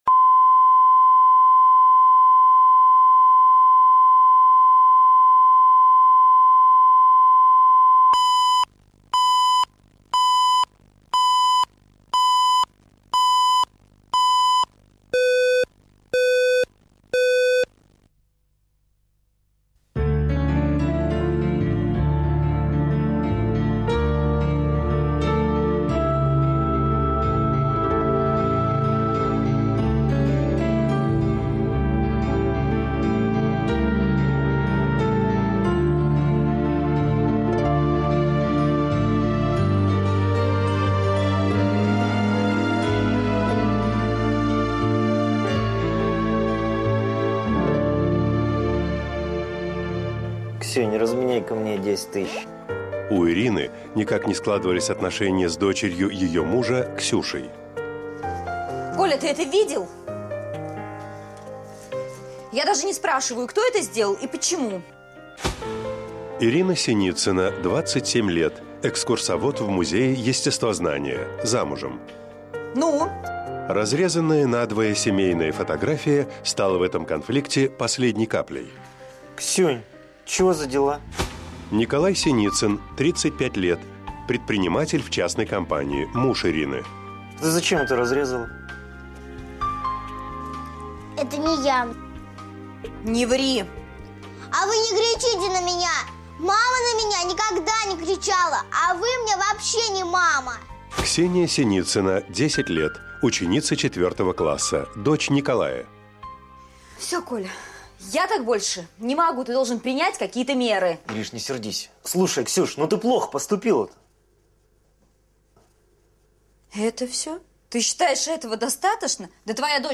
Аудиокнига Идеальный ребенок | Библиотека аудиокниг